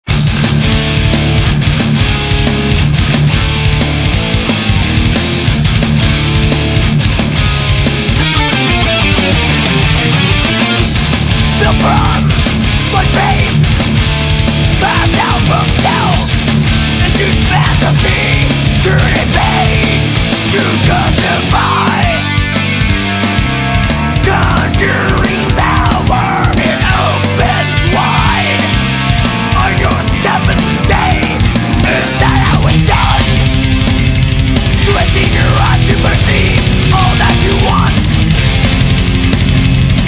All the samples on this page are 22khz/44khz,16bit,stereo.